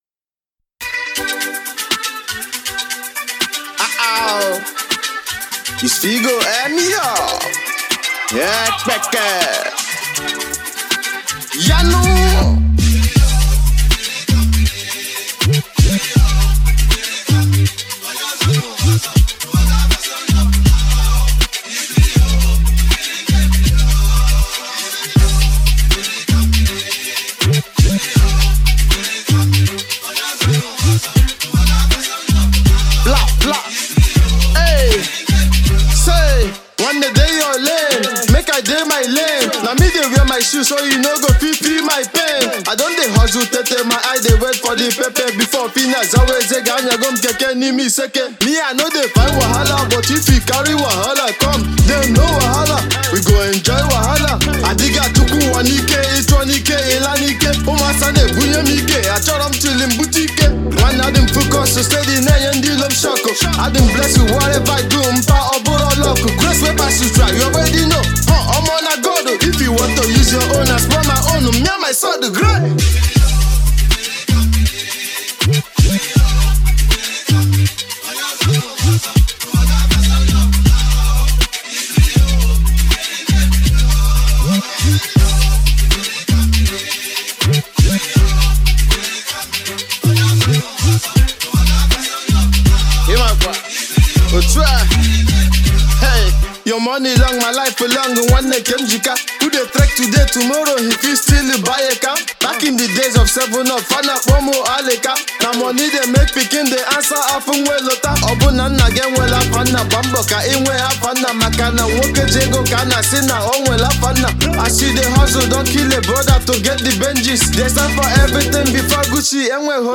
raw voice